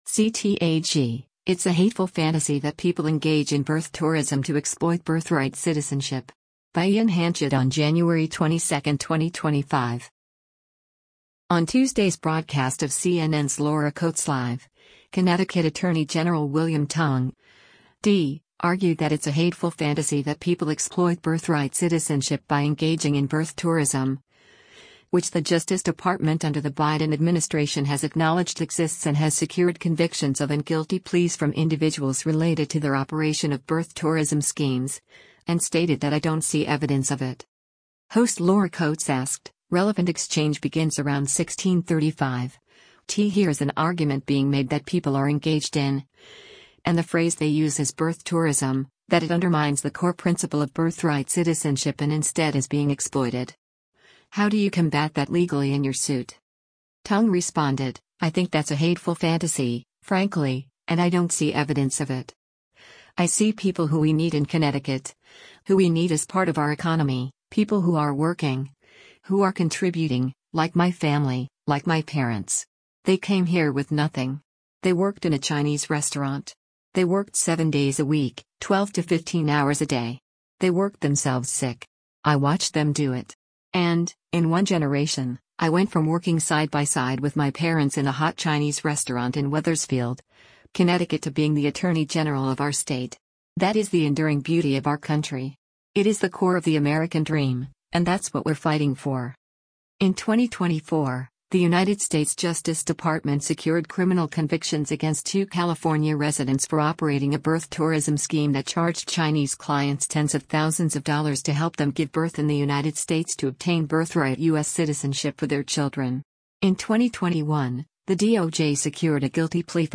On Tuesday’s broadcast of CNN’s “Laura Coates Live,” Connecticut Attorney General William Tong (D) argued that it’s “a hateful fantasy” that people exploit birthright citizenship by engaging in birth tourism — which the Justice Department under the Biden administration has acknowledged exists and has secured convictions of and guilty pleas from individuals related to their operation of birth tourism schemes — and stated that “I don’t see evidence of it.”